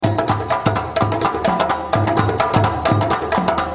LOOPS GRATUITS DE RYTHMES AFRICAINS
Rythme Africain - Djembes 17